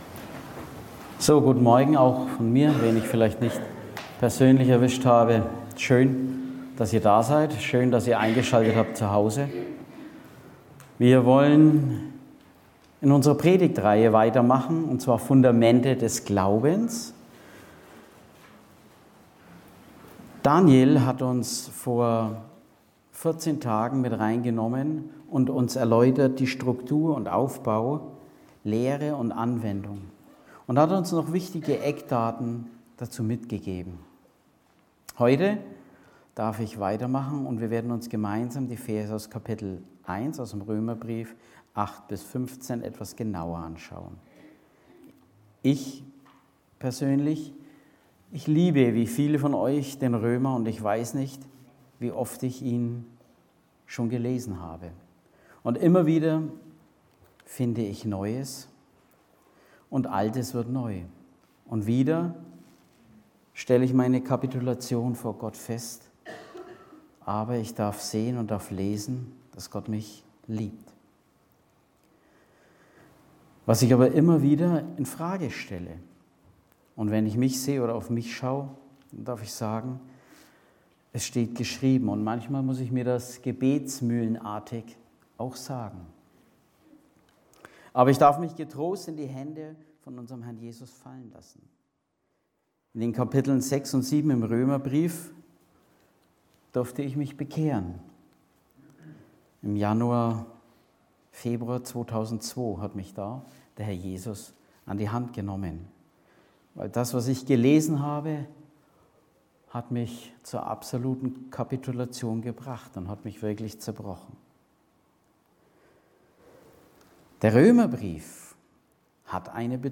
predigte